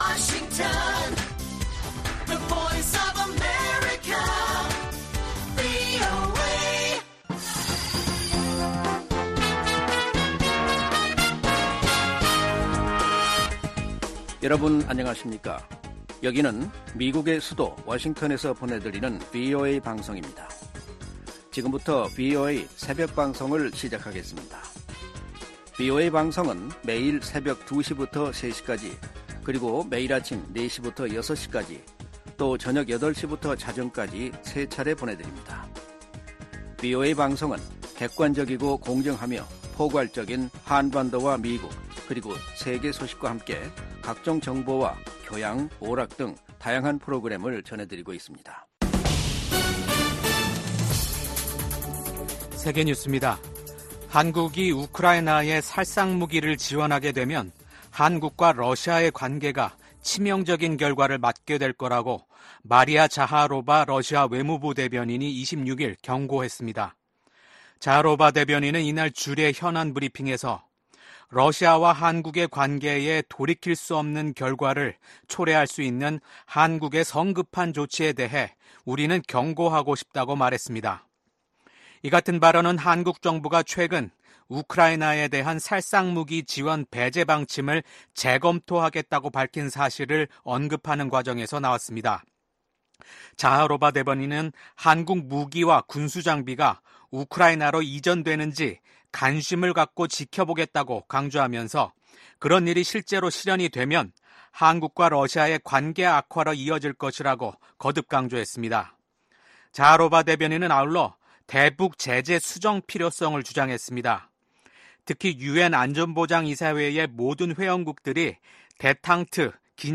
VOA 한국어 '출발 뉴스 쇼', 2024년 6월 27일 방송입니다. 북한이 동해상으로 극초음속 미사일로 추정되는 발사체를 쏘고 이틀째 한국을 향해 오물 풍선을 살포했습니다. 미국 정부는 북한의 탄도미사일 발사가 다수의 유엔 안보리 결의 위반이라며 대화에 복귀할 것을 북한에 촉구했습니다. 미국 국방부는 북한이 우크라이나에 병력을 파견할 가능성에 대해 경계를 늦추지 않고 있다는 입장을 밝혔습니다.